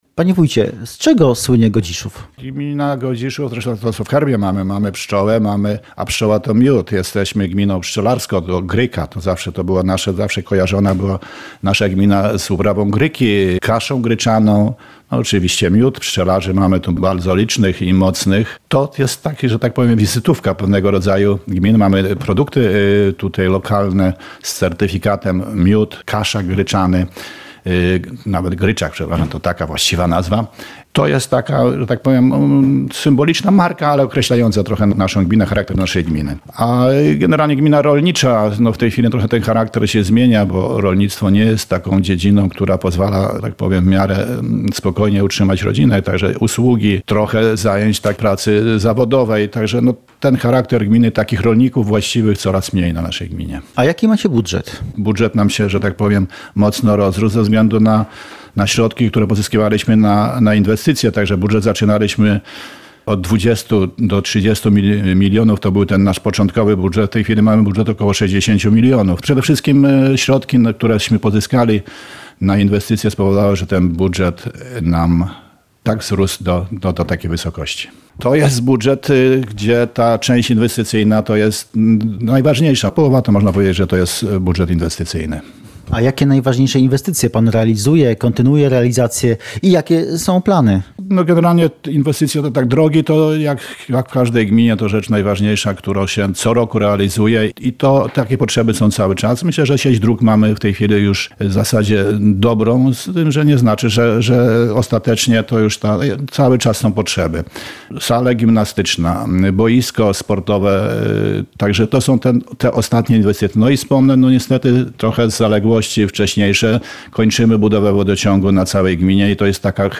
Cała rozmowa w materiale audio: